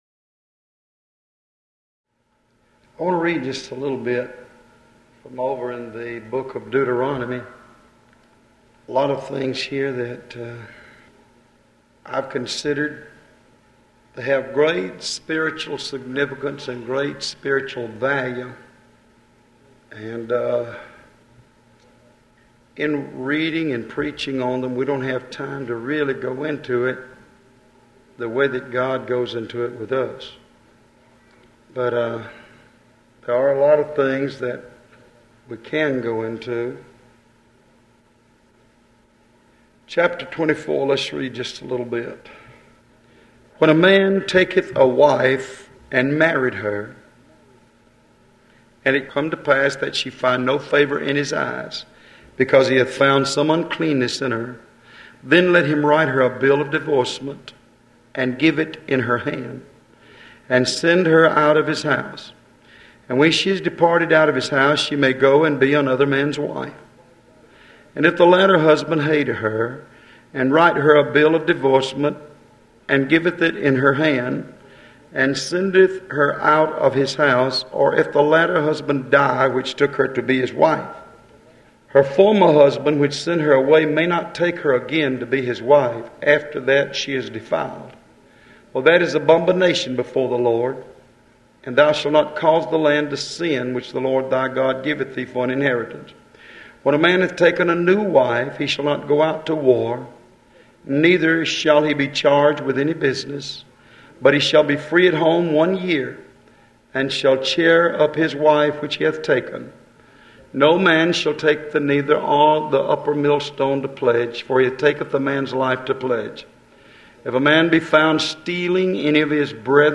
Sermons Starting With ‘A’